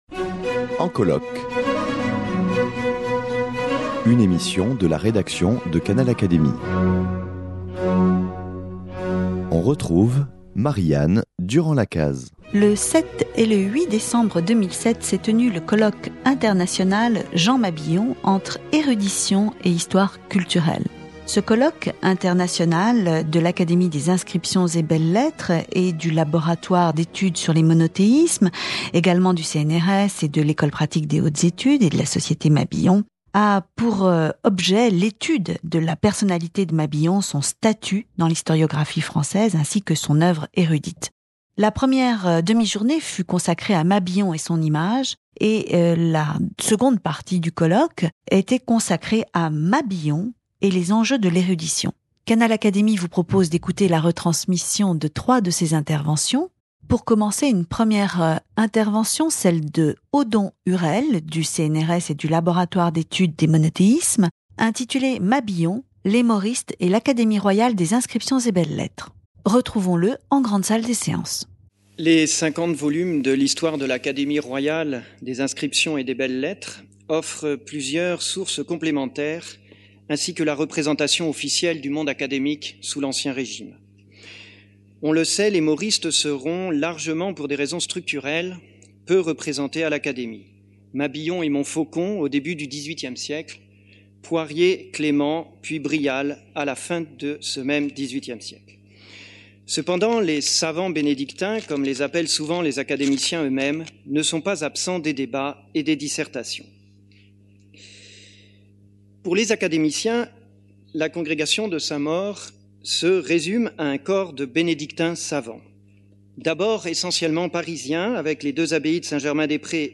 Découvrez en sa compagnie, comme si vous étiez en grande salle des séances, l’histoire de l’introduction de l’érudition historique par les Mauristes à l’Académie.